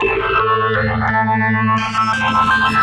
AC_OrganB_85-A2.wav